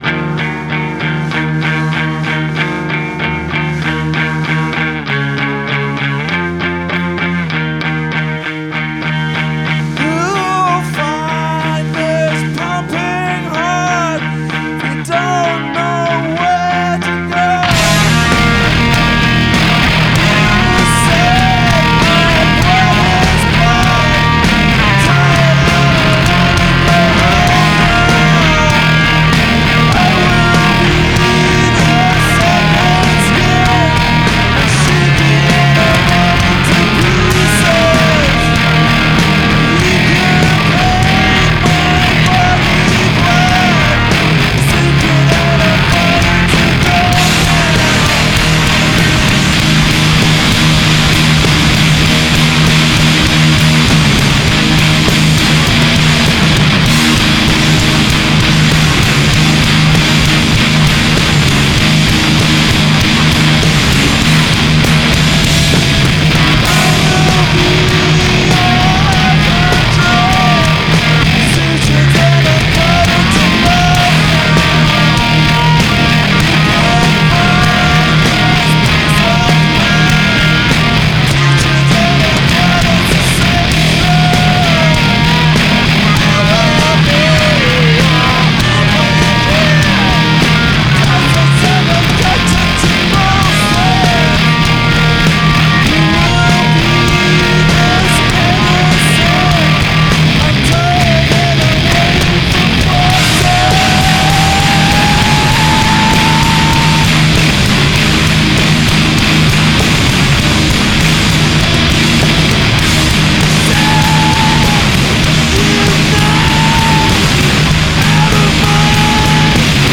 "Grand Masters of the neanderthal riff." (Uncut)